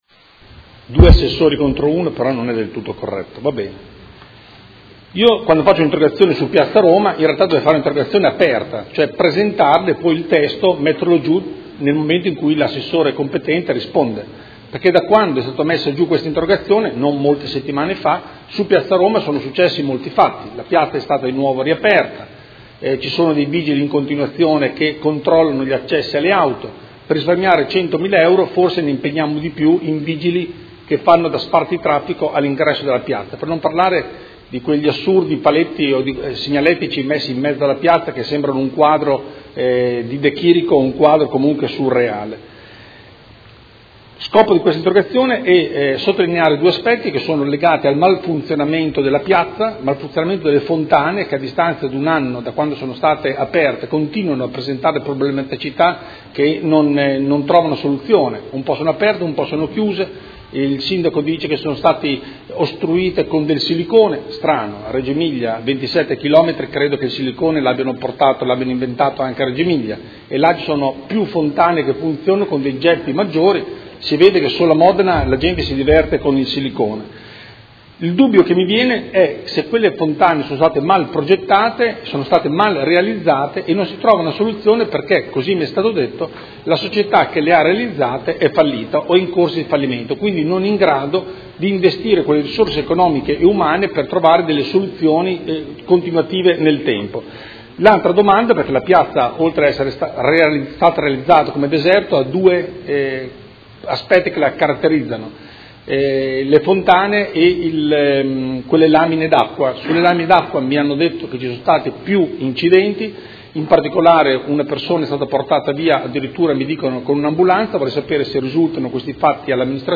Andrea Galli — Sito Audio Consiglio Comunale
Seduta del 27/10/2016 Interrogazione del Consigliere Galli (F.I.) avente per oggetto: Piazza Roma, ai malfunzionamenti si aggiungono gli incidenti?